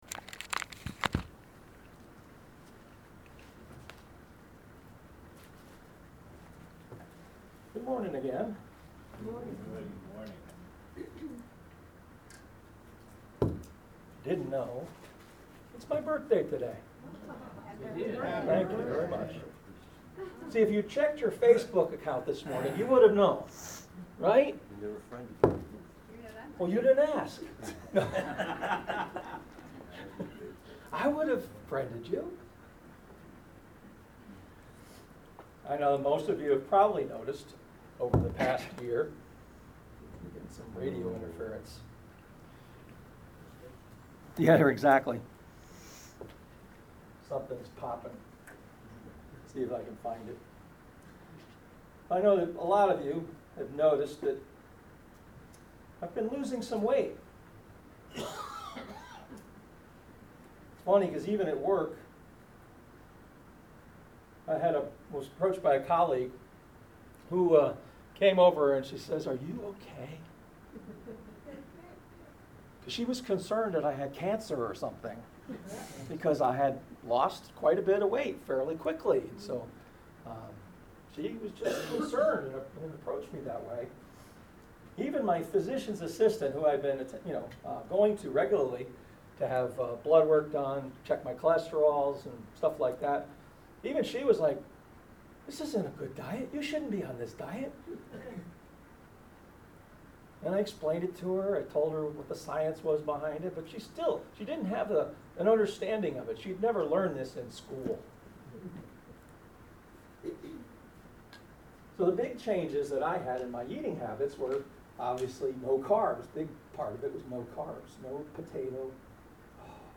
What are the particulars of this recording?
Luke 6:21 Service Type: Sunday Worship “Happy to be hungry” is a phrase that God has been putting in my mind frequently over these months.